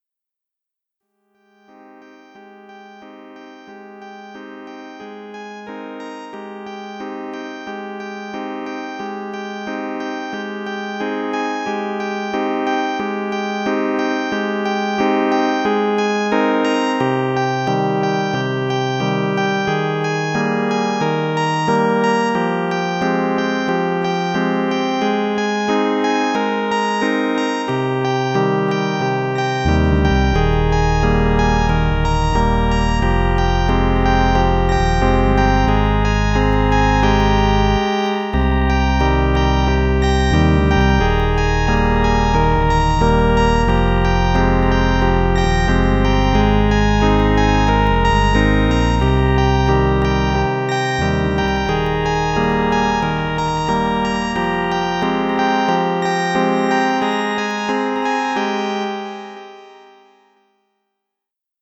Sad emotional warm rhodes theme.